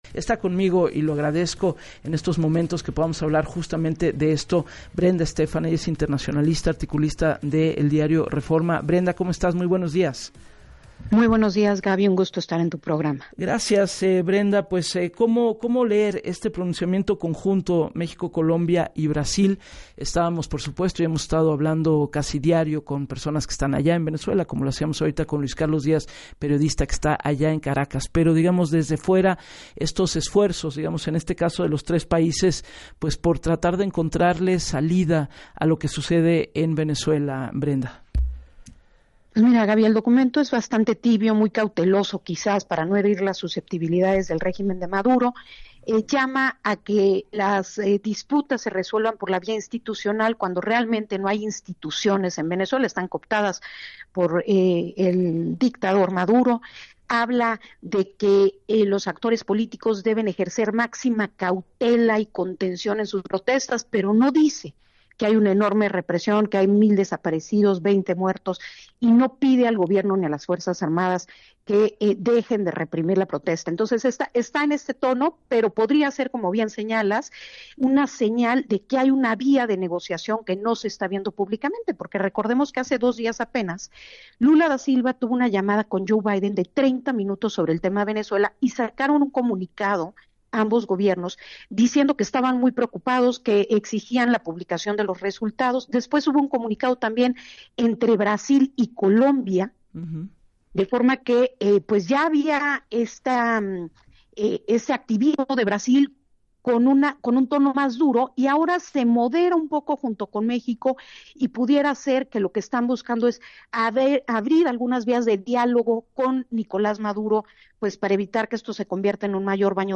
señala la internacionalista